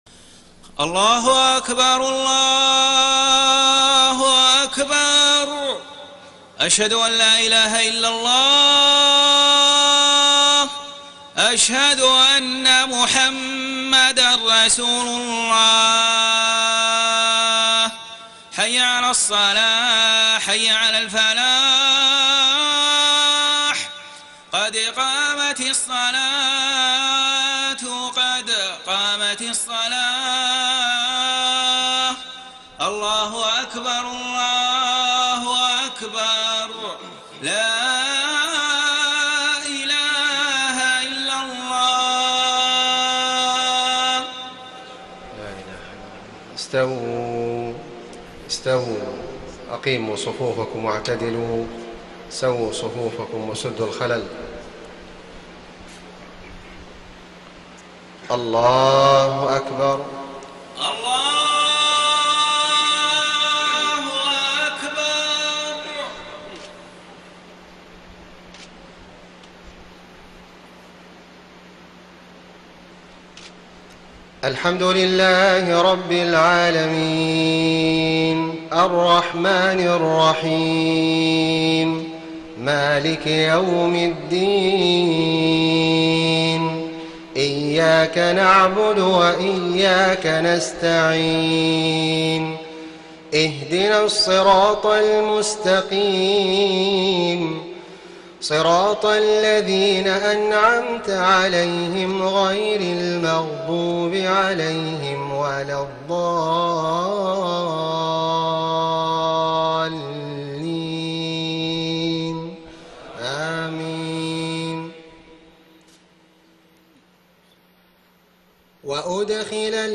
صلاة العشاء 14 ذو القعدة 1433هـ من سورة إبراهيم 23-27 > 1433 🕋 > الفروض - تلاوات الحرمين